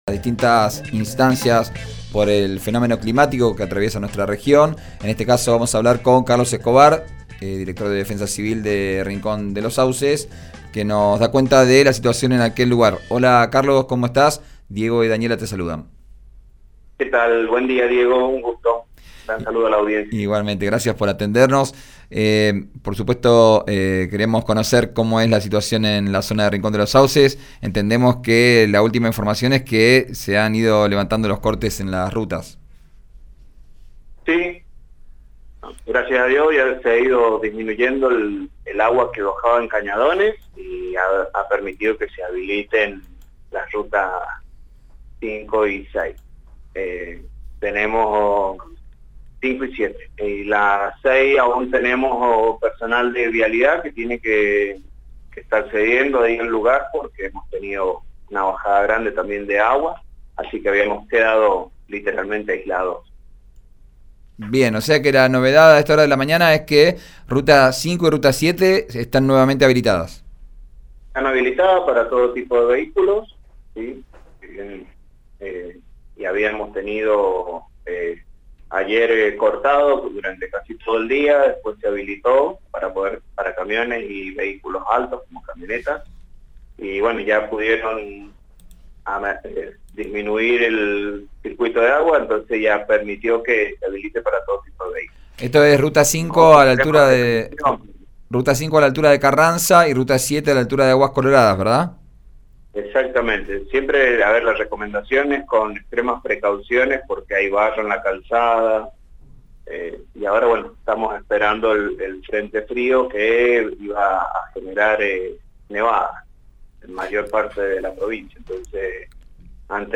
Escuchá a director de Defensa Civil de Rincón de los Sauces, Carlos Escobar en «Vos Al Aire», por RÍO NEGRO RADIO: